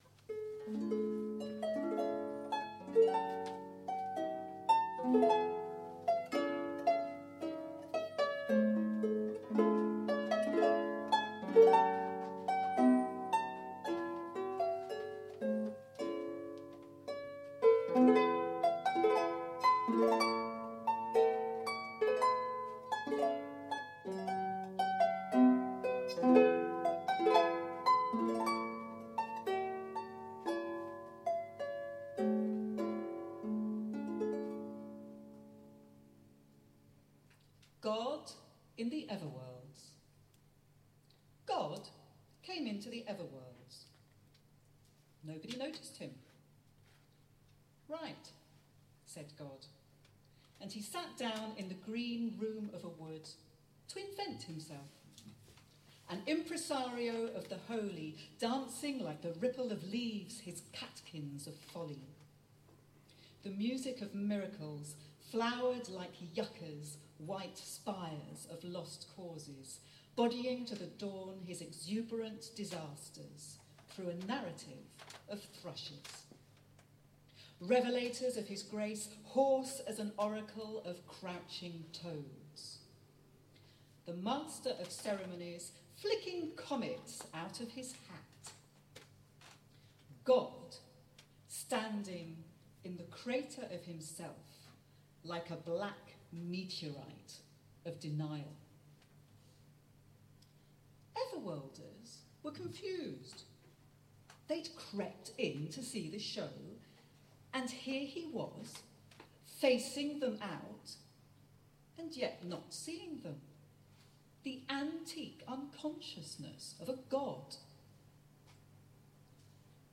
‘Tony Conran Remembered’ performed at Another Festival, Caernarfon July 2015